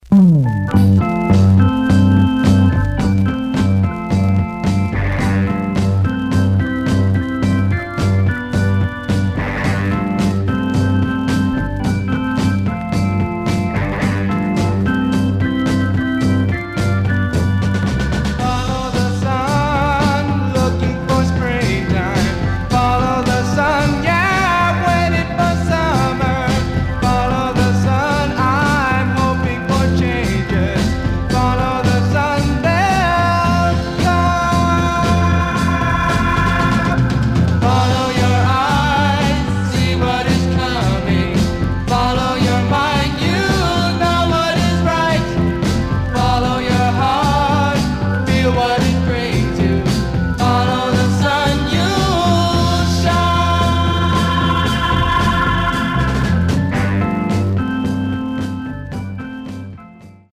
Surface noise/wear
Mono
Garage, 60's Punk